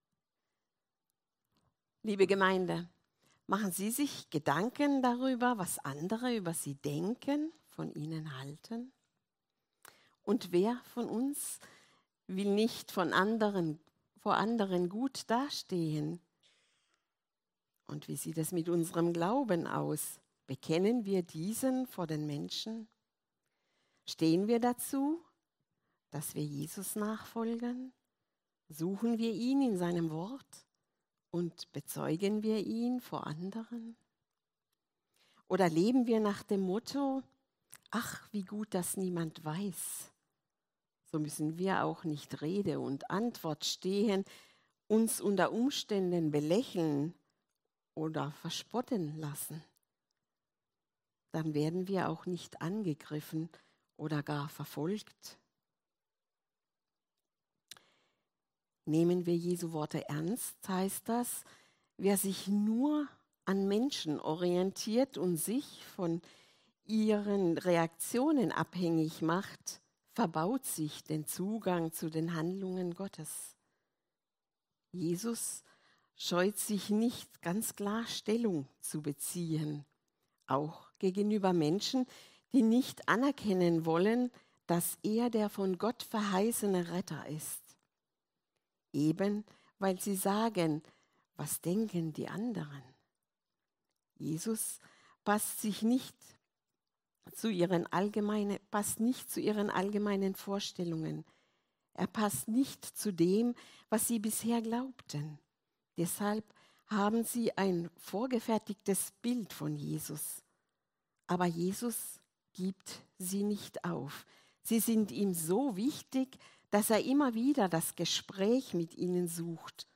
Predigten der Evangelischen Kirchengemeinde Durmersheim